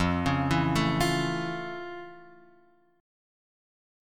F Augmented Major 7th